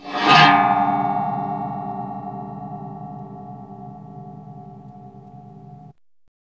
METAL HIT 11.wav